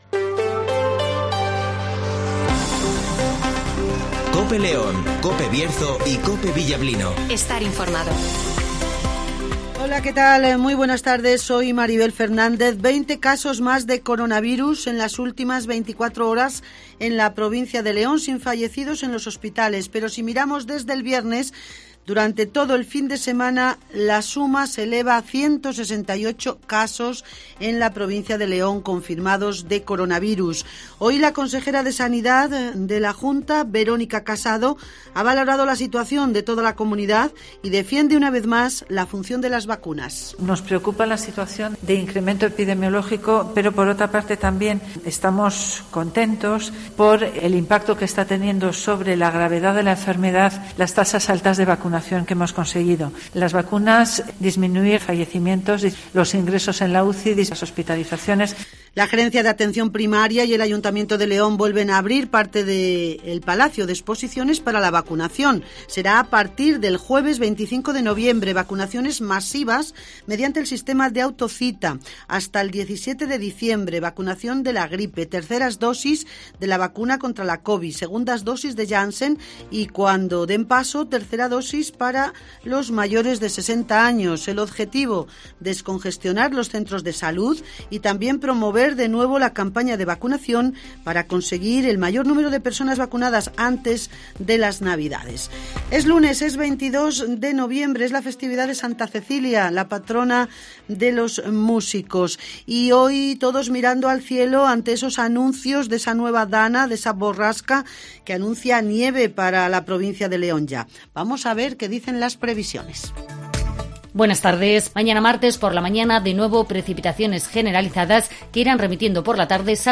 Informativo Mediodia en Cope León